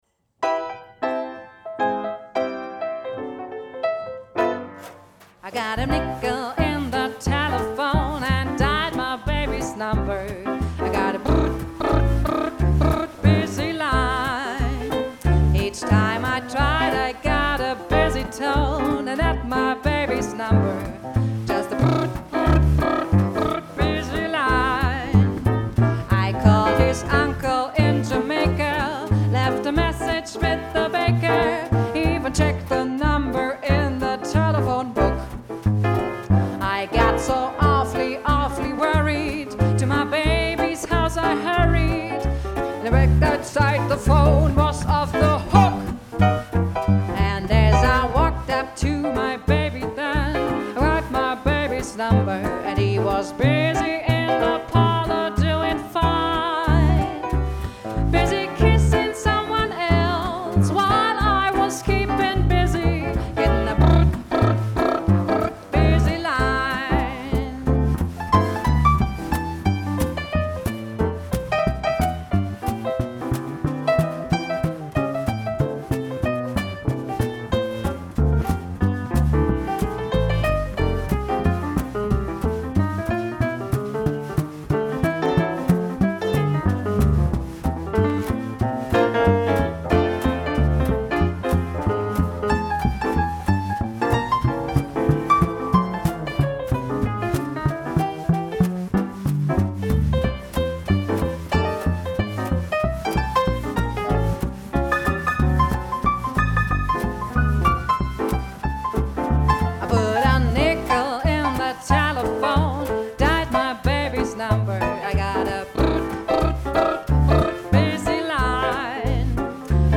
mit ihrer warmen Stimme zurück in die Blütezeit des Swing zaubern
an den Tasten unseres 1930er Pianos